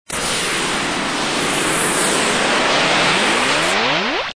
descargar sonido mp3 sintetizador 8